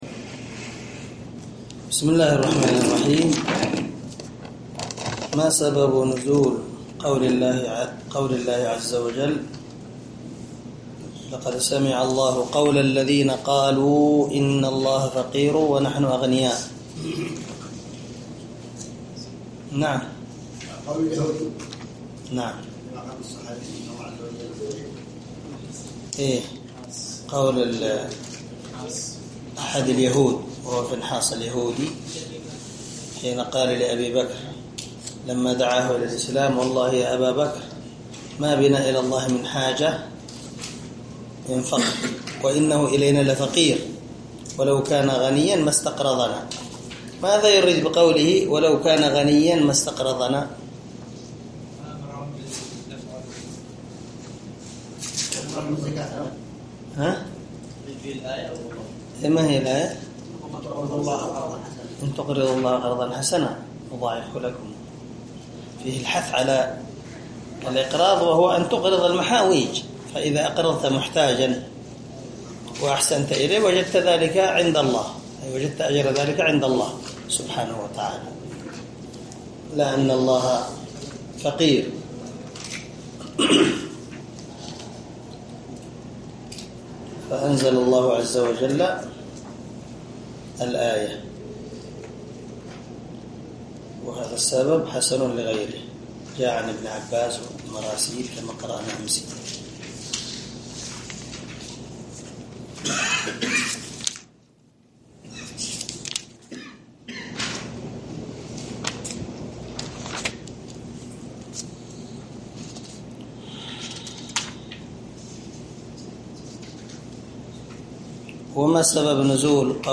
عنوان الدرس: الدرس الثلاثون
دار الحديث- المَحاوِلة- الصبيحة.